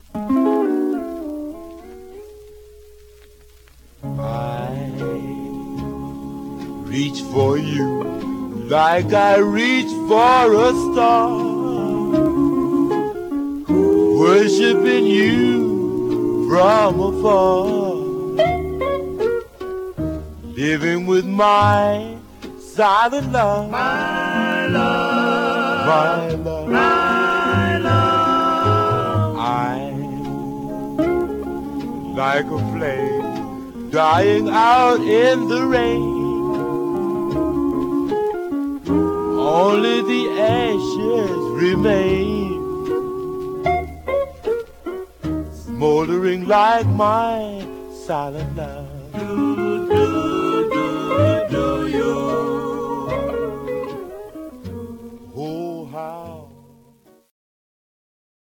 Condition Some surface noise/wear Stereo/mono Mono